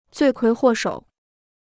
罪魁祸首 (zuì kuí huò shǒu) Free Chinese Dictionary